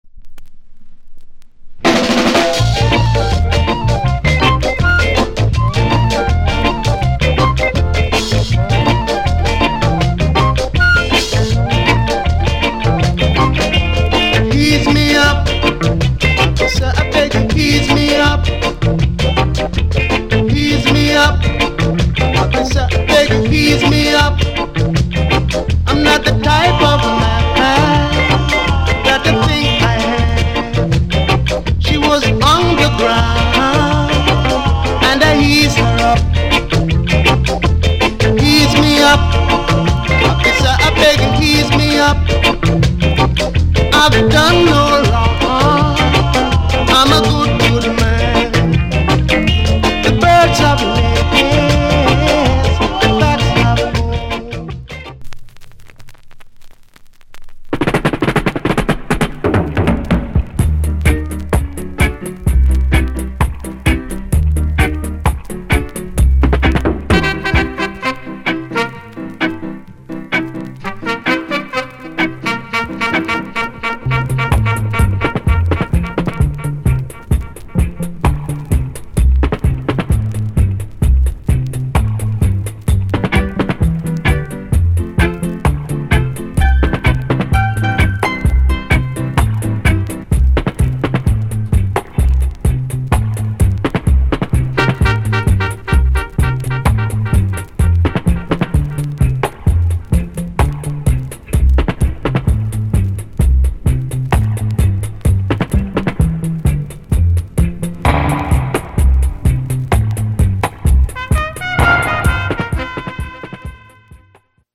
Genre Early Reggae / [A] Male Vocal Group Vocal [B] Dubwise